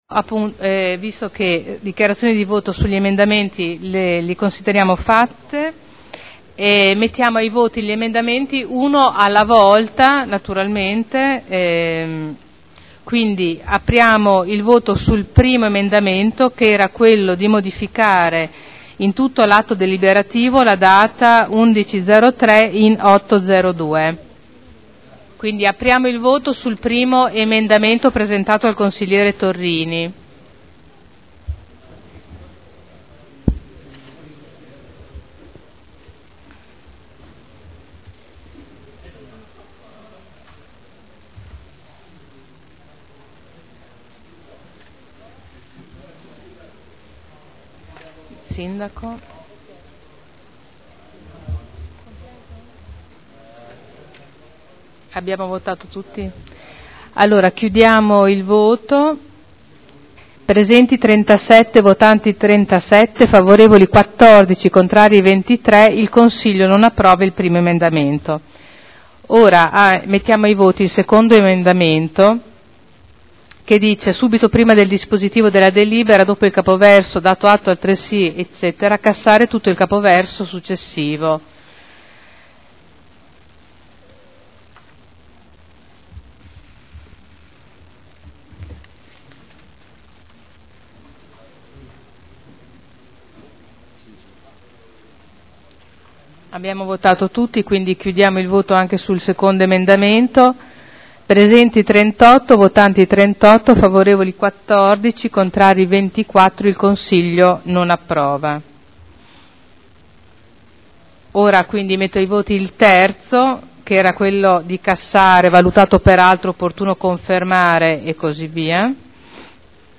Presidente — Sito Audio Consiglio Comunale
Seduta del 12/03/2012. Mette ai voti i tre emendamenti del consigliere Torrini.